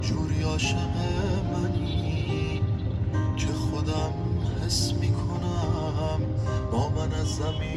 arabic_music.mp3